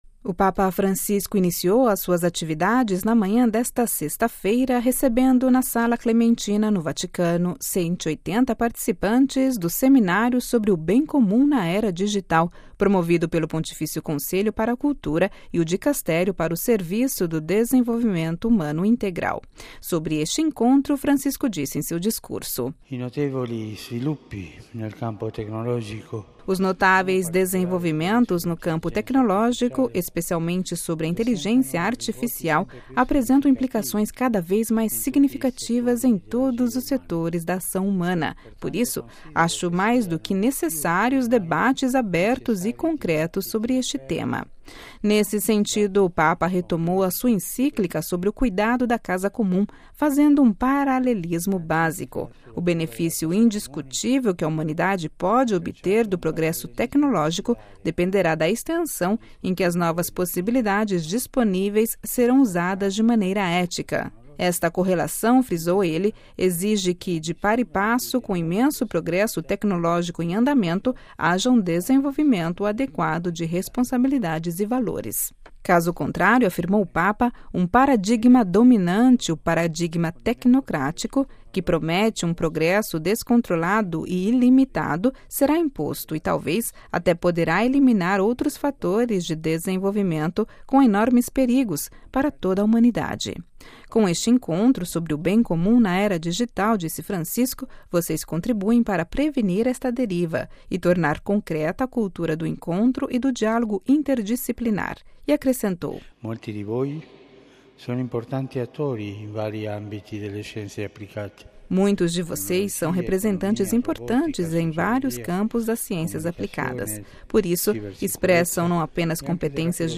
Ouça a reportagem completa com a voz do Papa Francisco